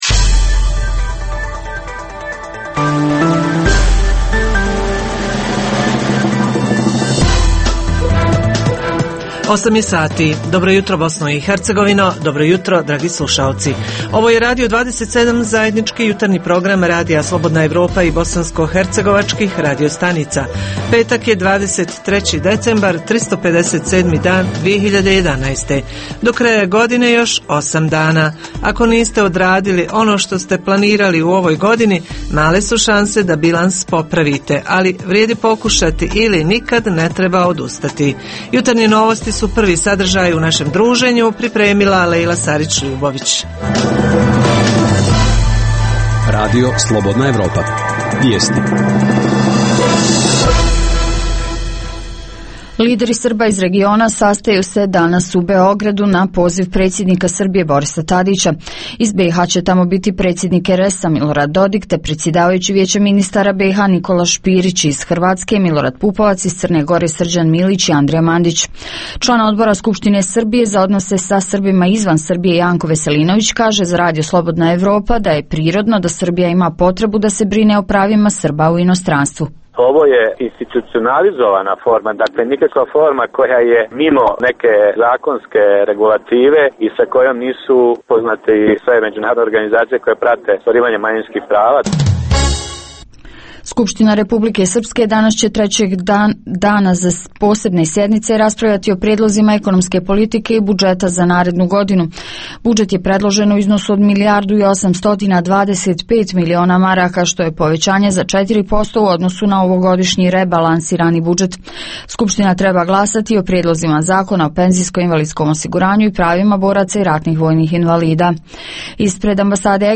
Položaj kulturnih institucija u našim gradovima – s obzirom da, zbog nedostatka novca, prijeti zatvaranje muzeja, biblioteka, galerija i ostalog na državnom nivou. Reporteri iz cijele BiH javljaju o najaktuelnijim događajima u njihovim sredinama.
Redovni sadržaji jutarnjeg programa za BiH su i vijesti i muzika.